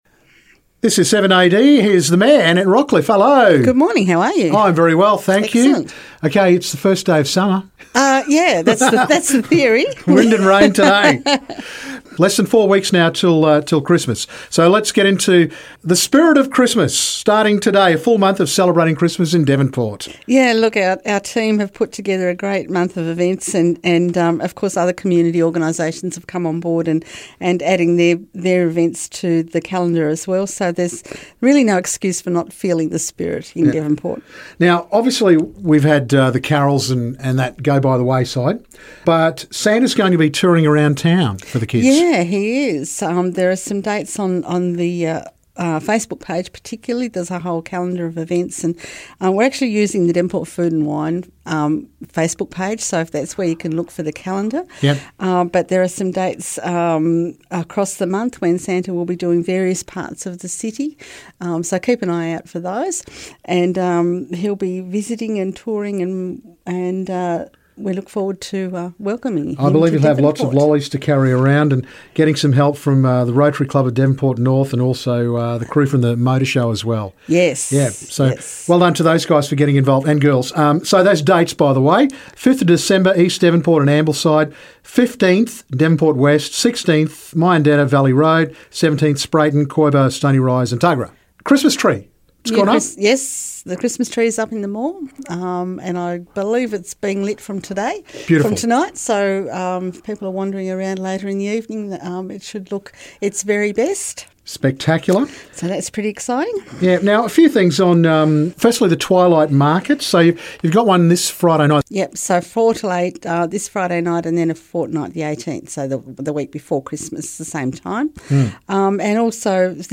Devonport Mayor Annette Rockliff on Christmas info and events.